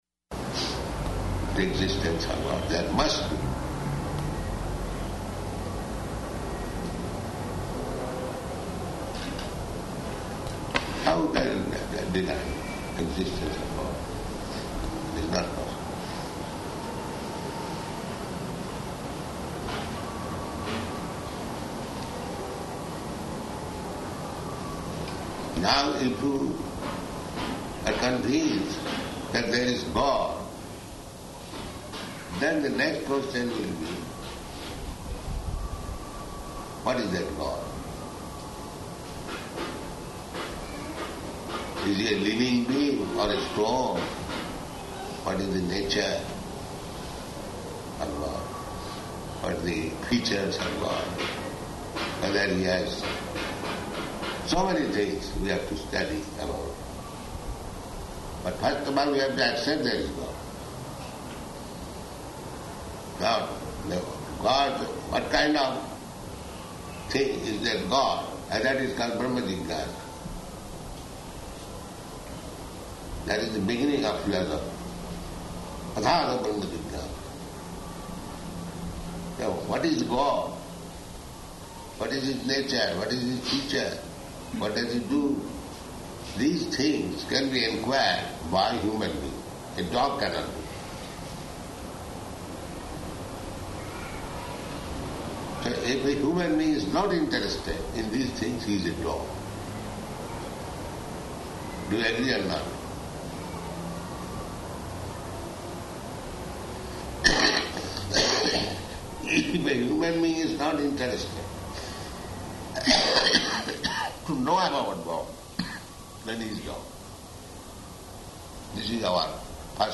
Evening Darśana --:-- --:-- Type: Conversation Dated: August 11th 1976 Location: Tehran Audio file: 760811ED.TEH.mp3 Prabhupāda: ...the existence of God.